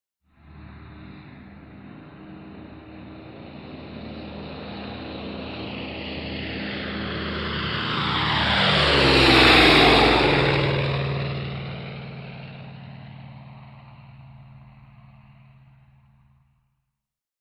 AIRCRAFT PROP SINGLE ENGINE: EXT: Fly by fast speed. Low pass by.